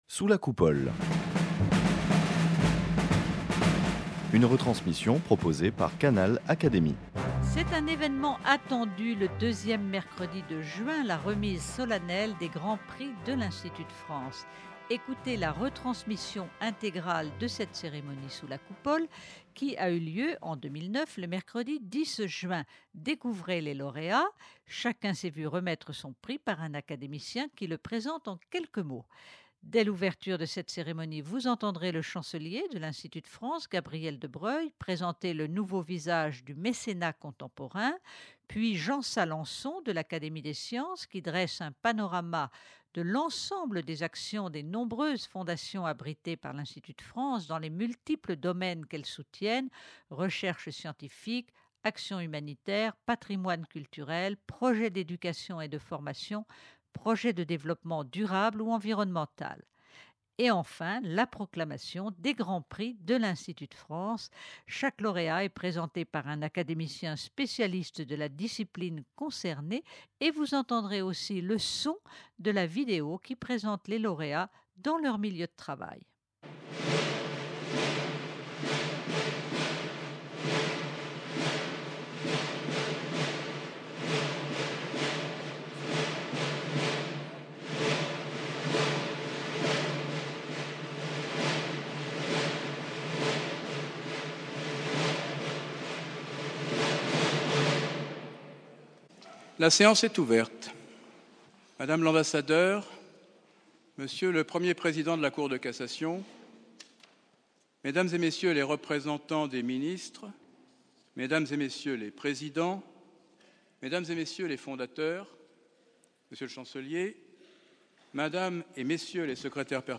Ecoutez la retransmission intégrale de cette cérémonie sous la Coupole qui a eu lieu, en 2009, le mercredi 10 juin. Découvrez-les lauréats : chacun s’est vu remettre son prix par un académicien qui le présente.
Dès l'ouverture de cette cérémonie, vous entendrez le Chancelier de l'Institut de France, Gabriel de Broglie, présenter le nouveau visage du mécénat contemporain.